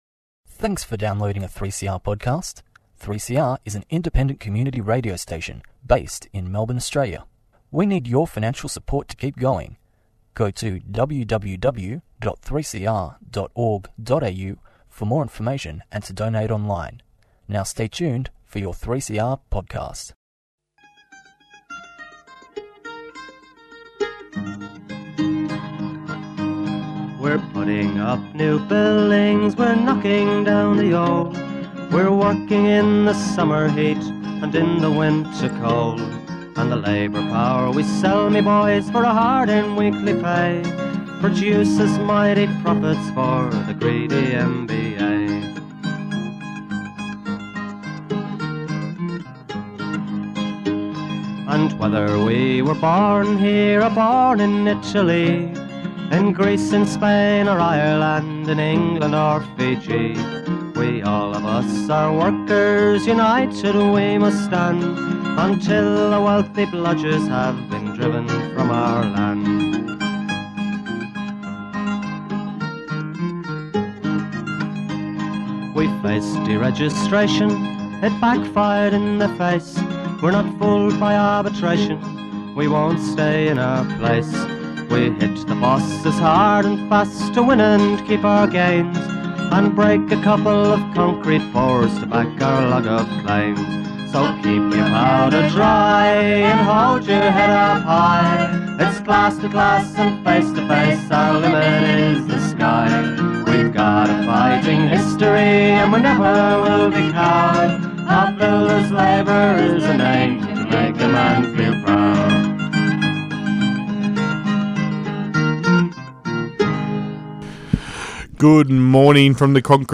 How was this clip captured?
Raw community radio at its best, The Concrete gang is most (in)famous for "Scallywag of the Week" where names are named and the gloves come off.